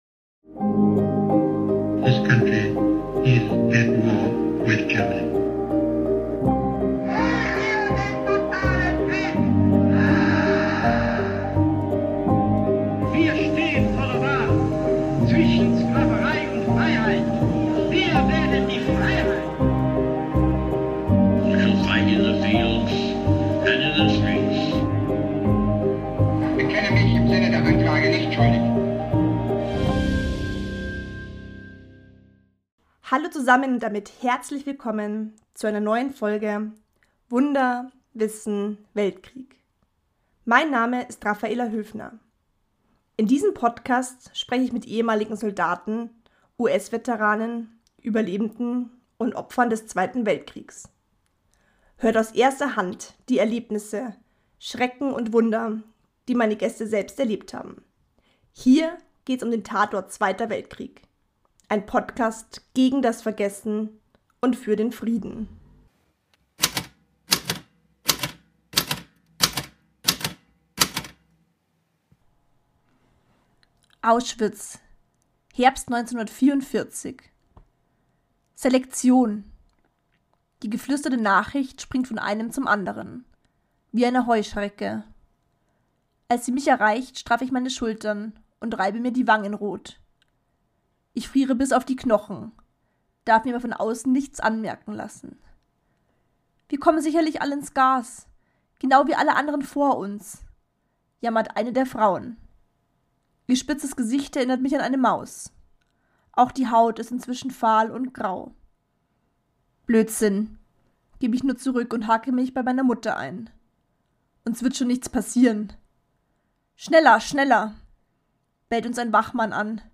Hört jetzt rein in das Interview, um Ditas Erzählung aus erster Hand zu erfahren. Interviewgast in dieser Folge: Dita Kraus